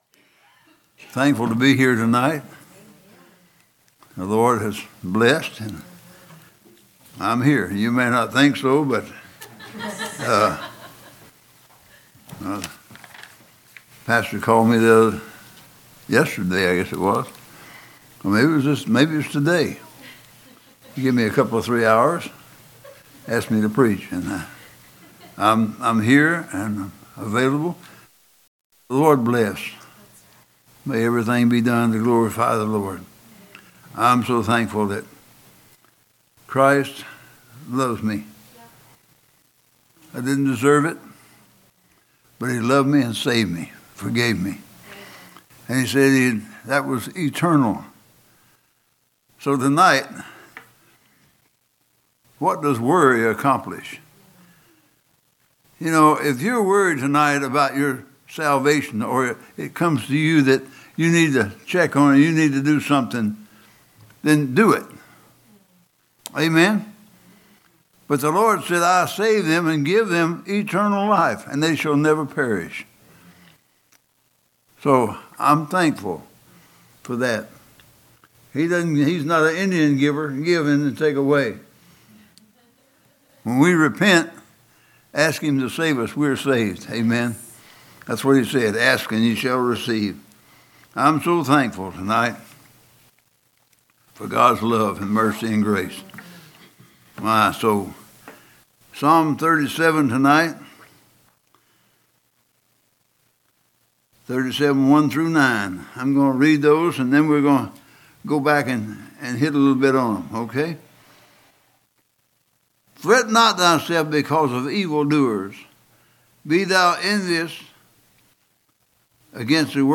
A message from the series "General Preaching."
From Series: "General Preaching"